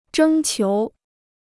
征求 (zhēng qiú): to solicit; to seek.